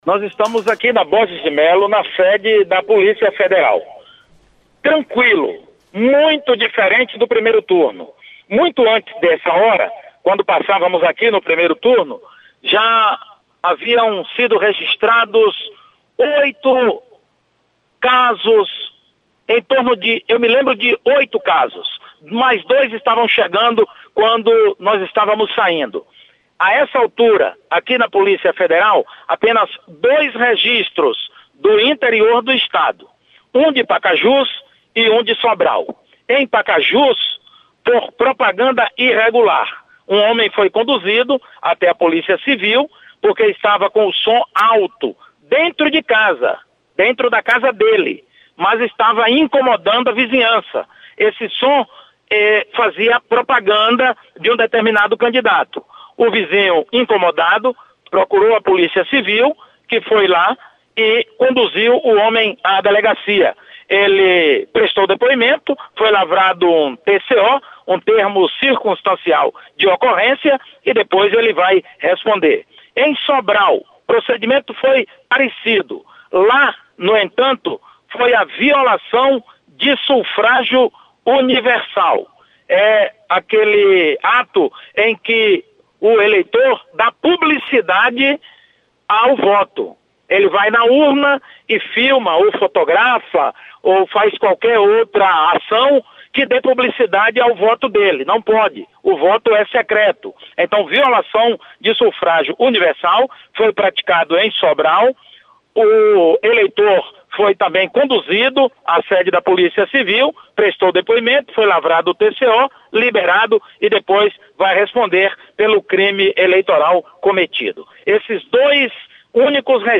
Polícia Federal registra duas ocorrências no Ceará. Repórter